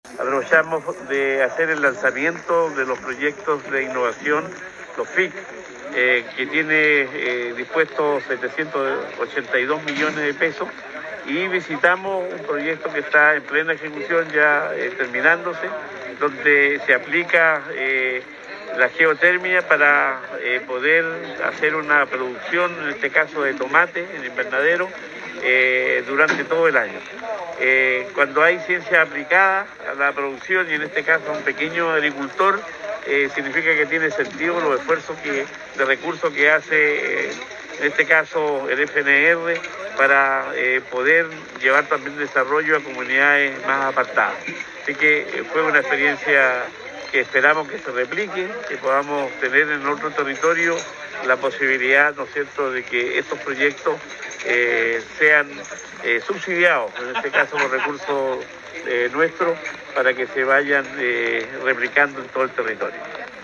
Cuña_Gobernador-Cuvertino_FIC-2021.mp3